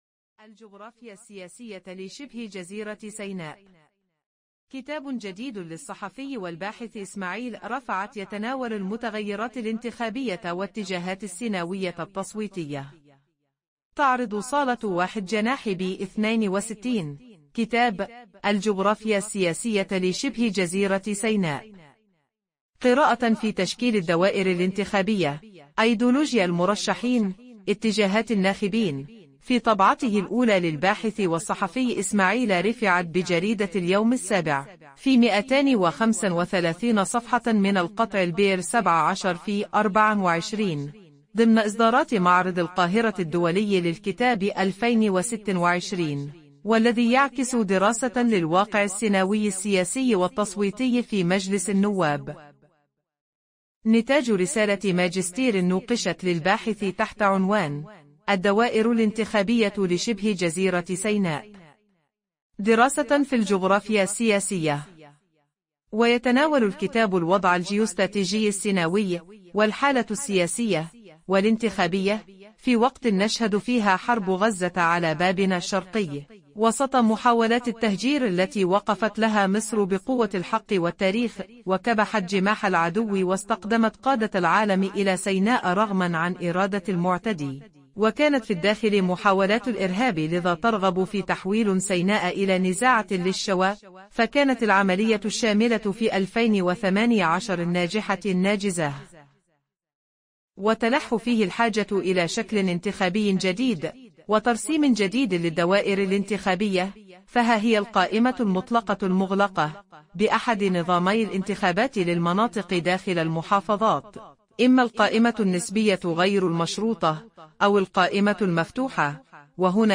عرض صوتي